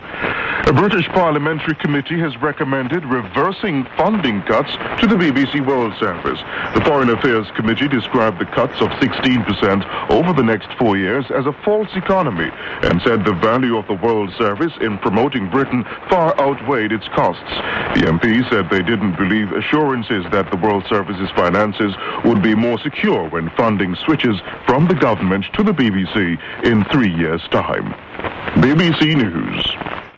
(click here to listen to hear recording) (UTC) I heard on the BBC New the cuts would be reversed.